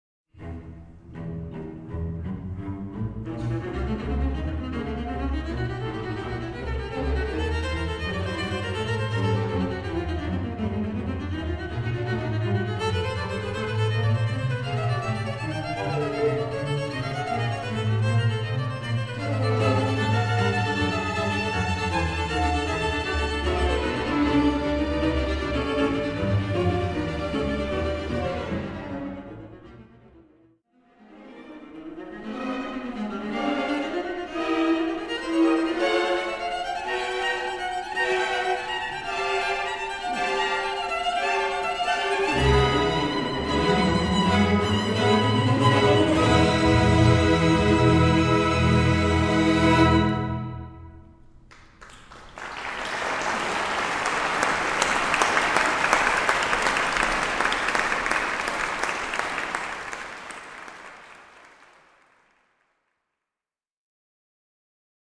for viola solo and string orchestra